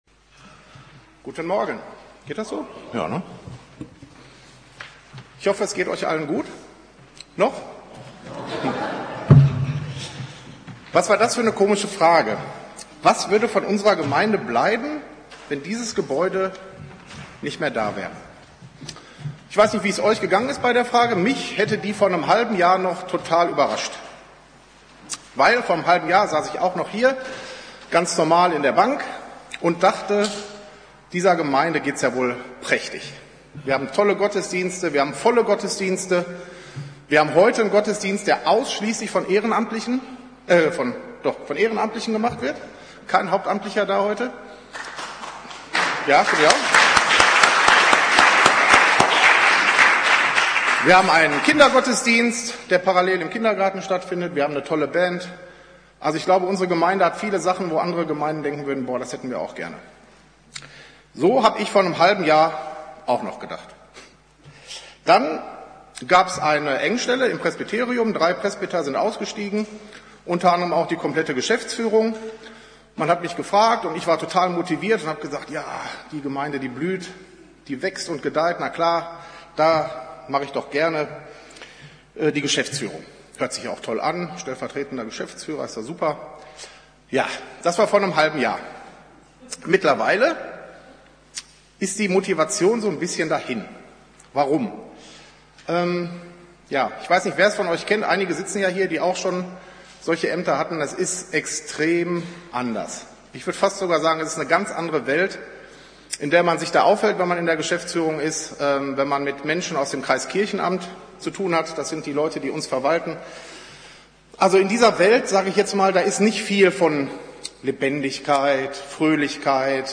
Langschläfergottesdienst
Juli 7, 2019 | Predigten | 0 Kommentare